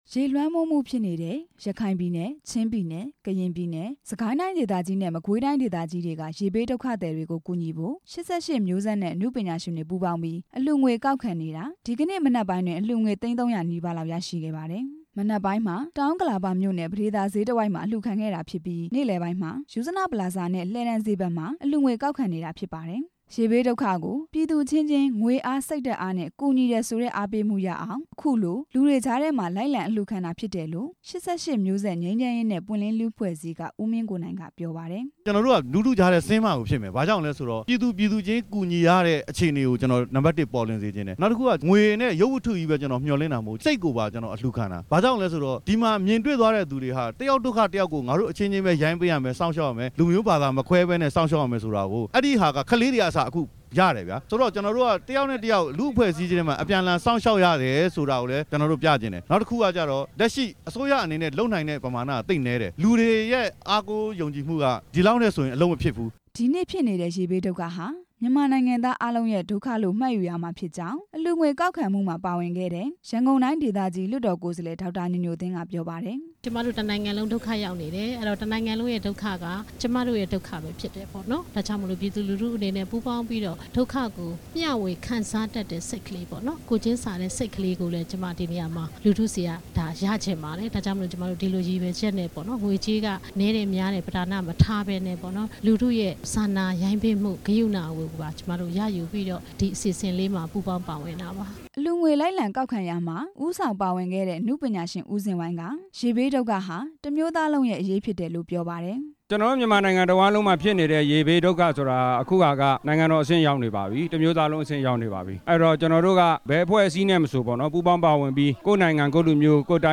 ရေဘေးအတွက် ရန်ကုန်မှာ အလှူငွေကောက်ခံနေတဲ့အကြောင်း တင်ပြချက်